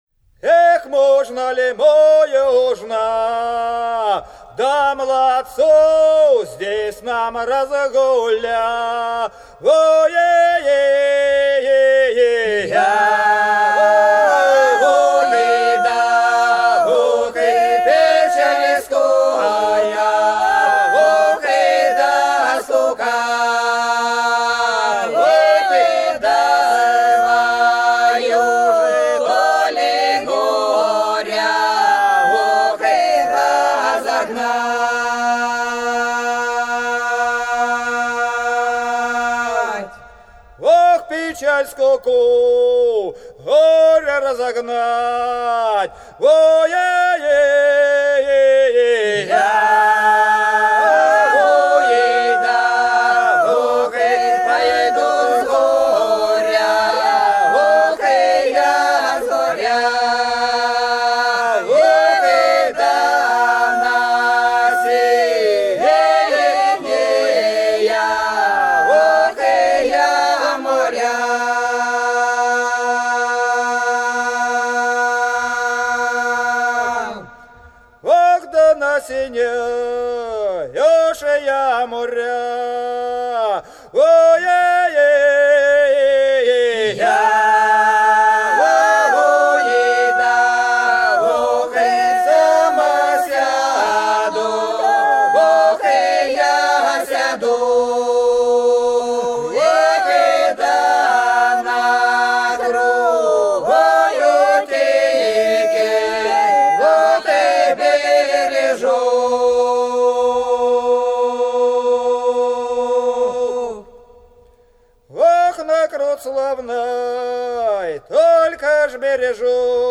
Долина была широкая (Поют народные исполнители села Нижняя Покровка Белгородской области) Можно ли молодцу здесь разгуляться - протяжная